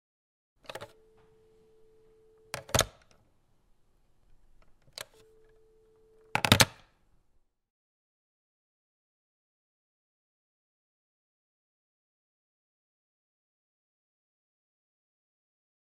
دانلود صدای قطع تلفن2 از ساعد نیوز با لینک مستقیم و کیفیت بالا
جلوه های صوتی
برچسب: دانلود آهنگ های افکت صوتی اشیاء دانلود آلبوم صدای گذاشتن گوشی یا قطع تلفن از افکت صوتی اشیاء